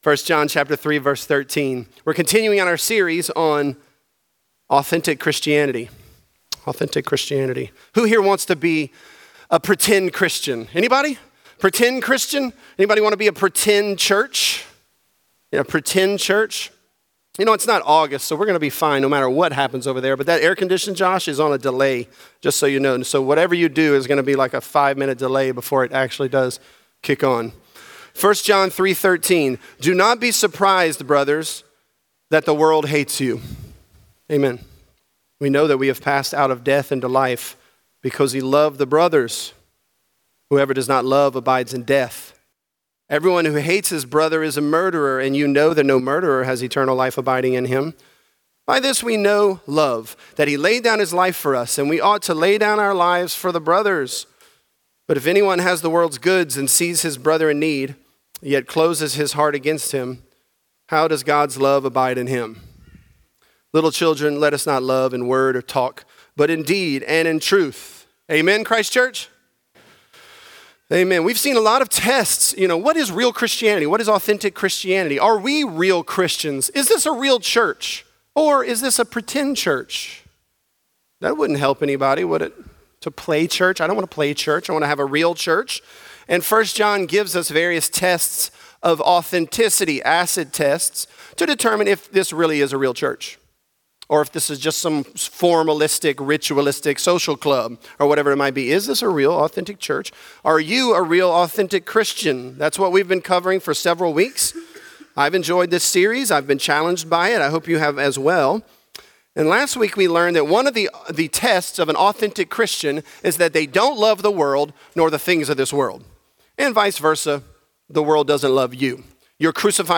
Authentic: Deeds Done From The Heart | Lafayette - Sermon (1 John 3)